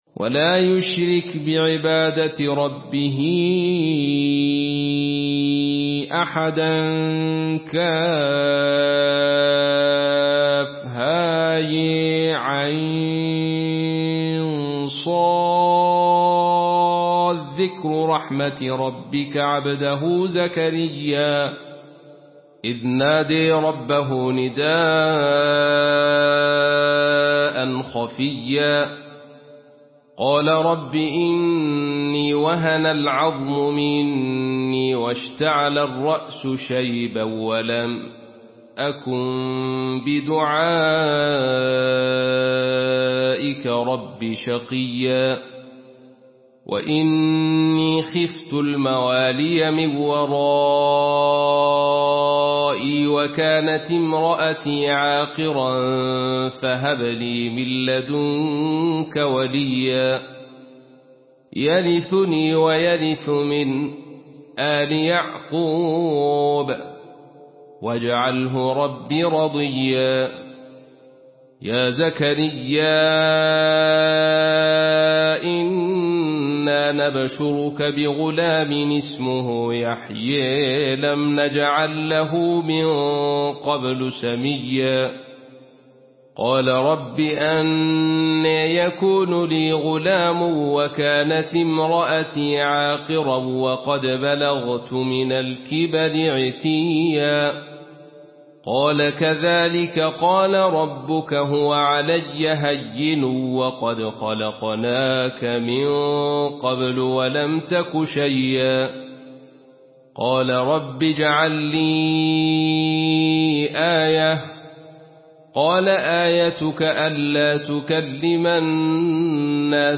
Surah Maryam MP3 in the Voice of Abdul Rashid Sufi in Khalaf Narration
Murattal Khalaf An Hamza